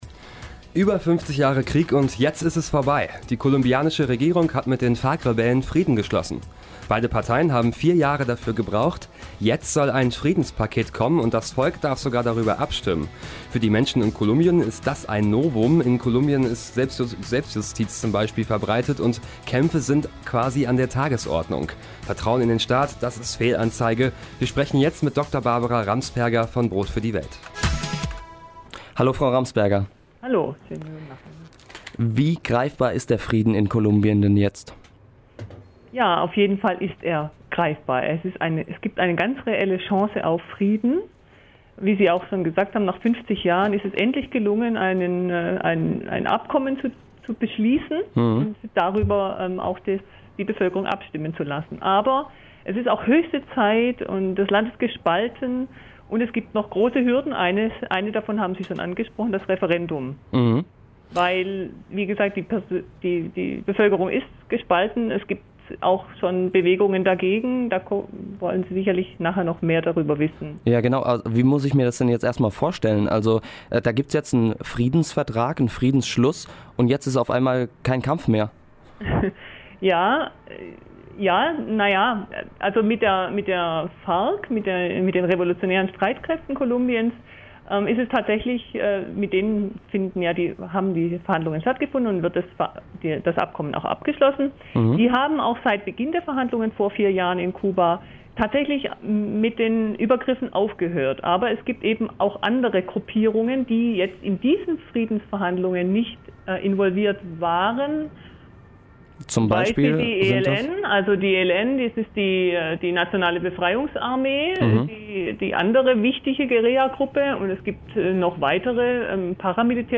Am 26. August 2016 in Radio